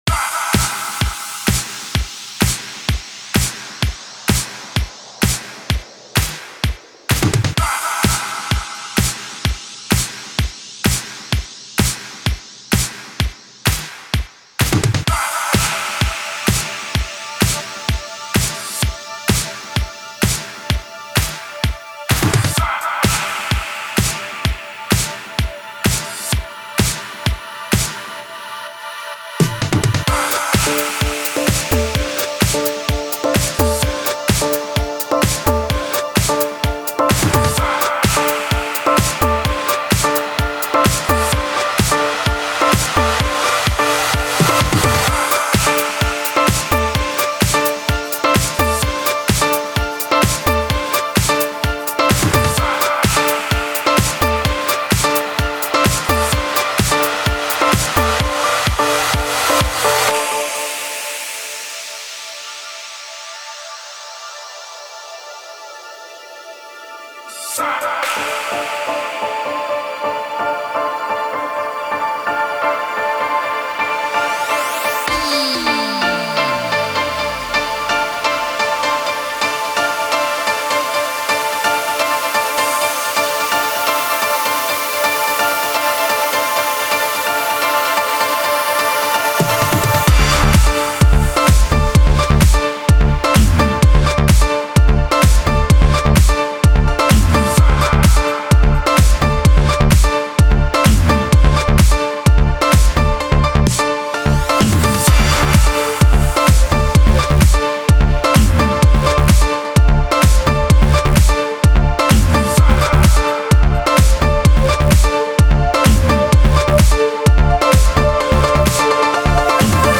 Жанр:House